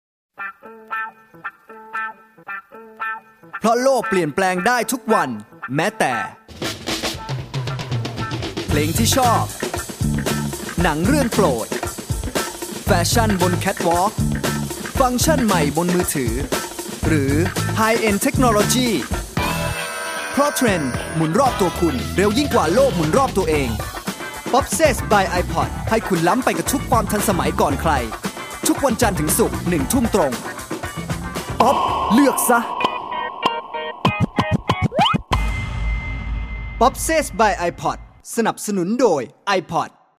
i have a home recording studio with considerable audio equipments such as Nueman TLM 103 microphone, Focusrite Class A preamp, KRK V6 studio monitor and Digidesign Mbox.
I am a professional Thai voice talent.
Sprechprobe: Sonstiges (Muttersprache):